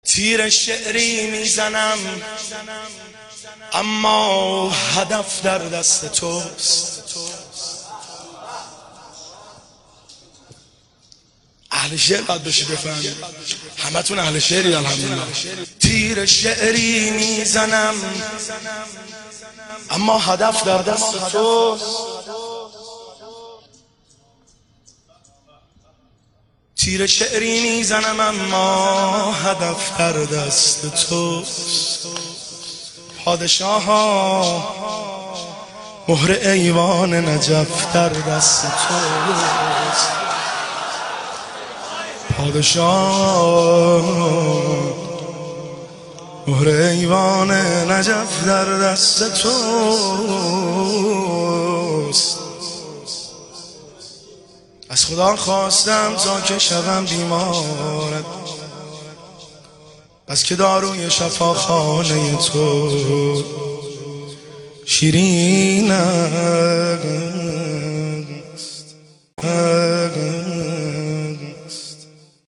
شعرخوانی زمزمه شب 19 رمضان المبارک 1394
هیئت بین الحرمین طهران